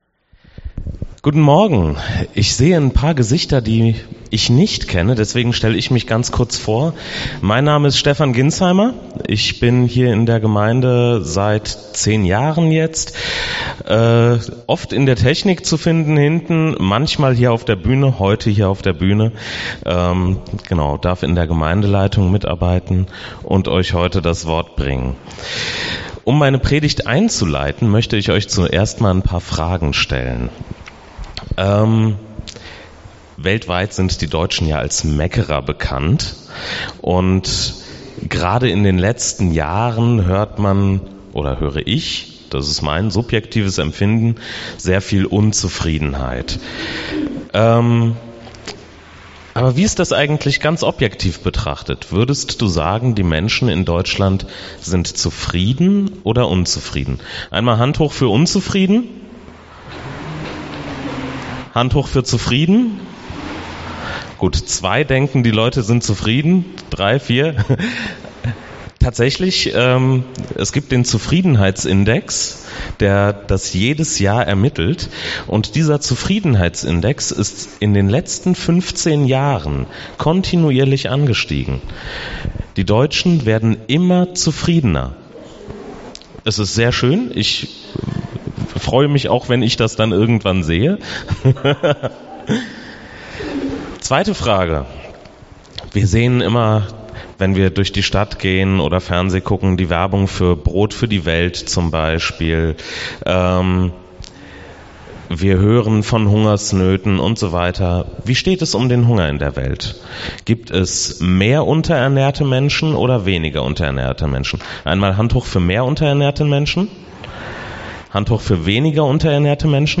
Bibelstelle zur Predigt: 2. Mose 4